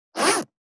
429,ジッパー,チャックの音,洋服関係音,ジー,バリバリ,カチャ,ガチャ,シュッ,パチン,ギィ,カリ,
ジッパー